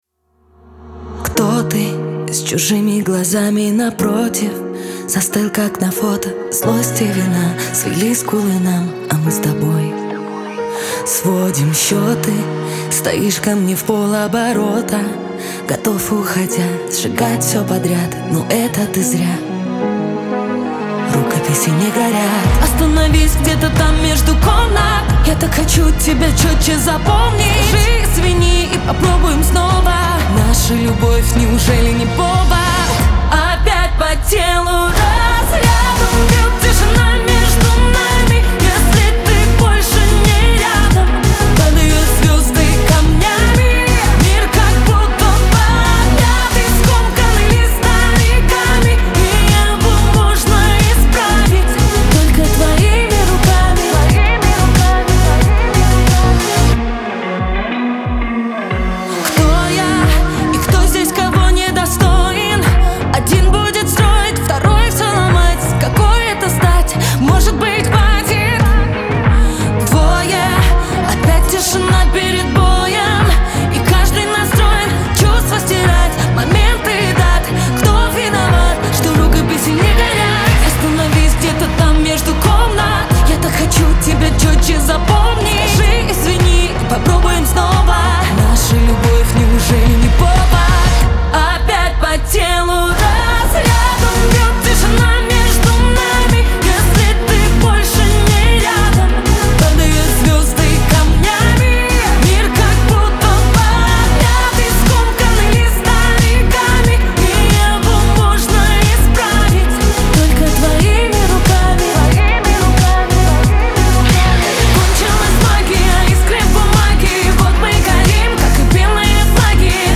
эстрада
pop , диско